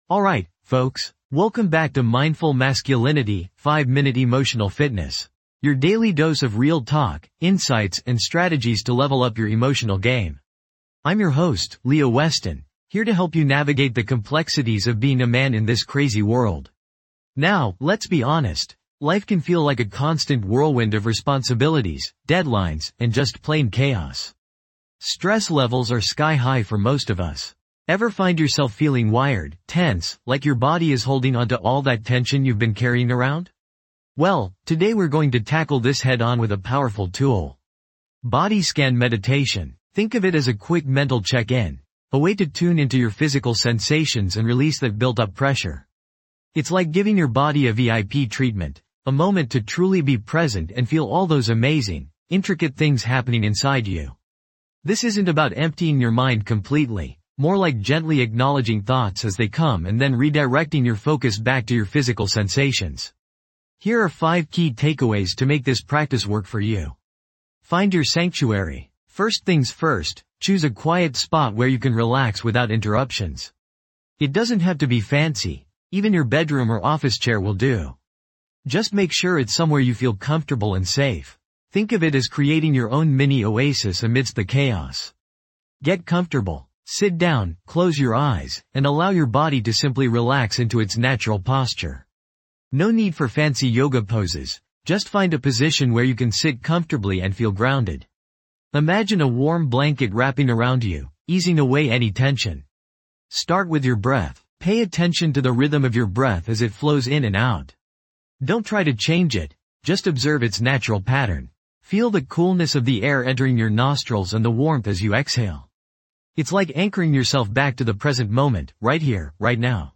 Dive deep into relaxation with a guided Body Scan Meditation.
This podcast is created with the help of advanced AI to deliver thoughtful affirmations and positive messages just for you.